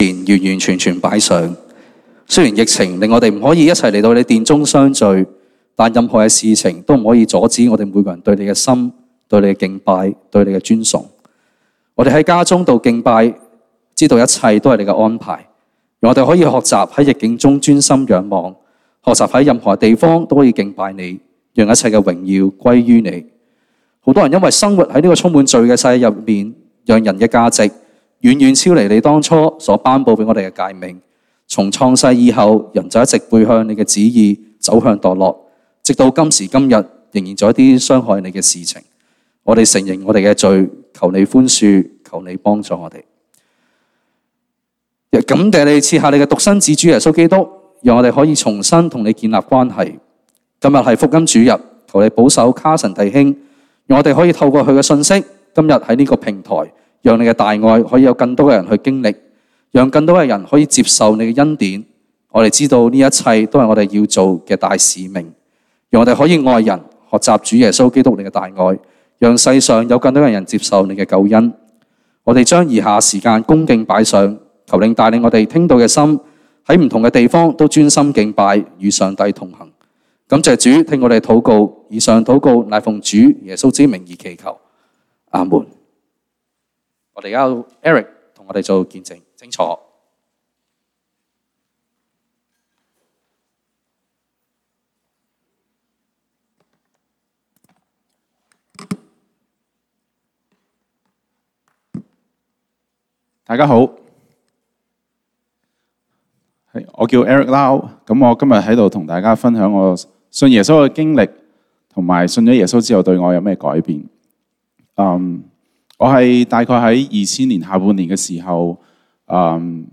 粵語堂福音主日